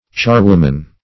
Charwoman \Char"wom`an\ (ch[^a]r"w[oo^]m`an), n.; pl.